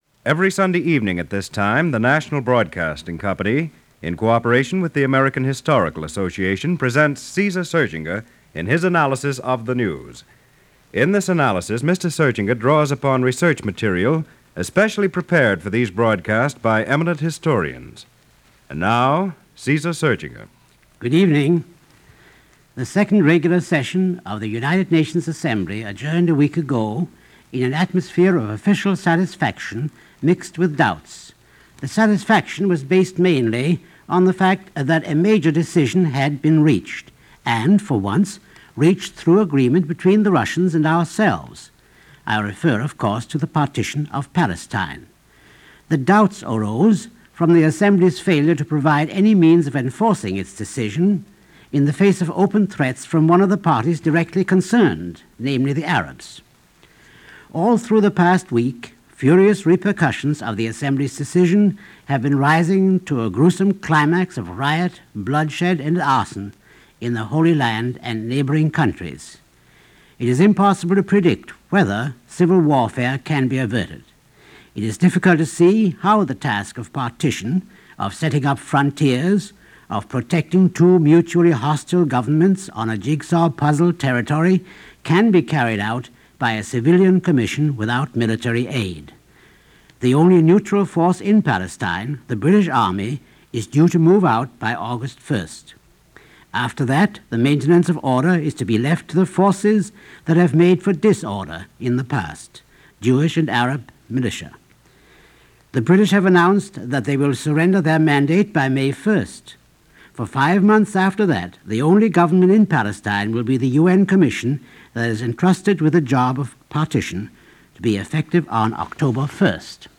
This broadcast, part of the Story Behind The Headlines series for NBC Radio, originally aired on December 7, 1948 talked about the on-going violence and opposition to the British Partition plan for Palestine and the establishment of Israel as an independent state.